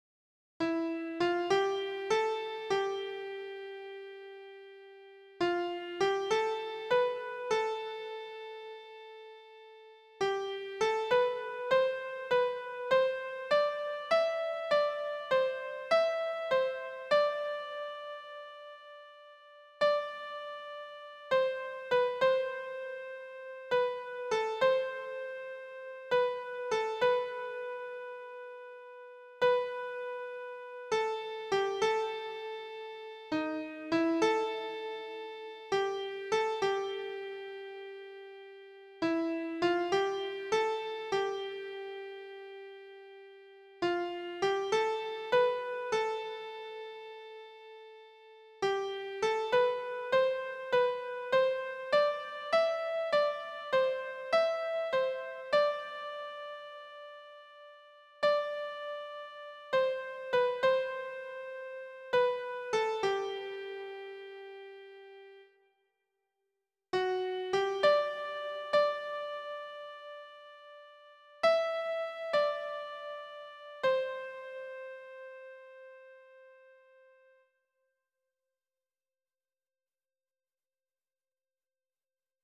esse_seu_olhar_-_soprano[50297].mp3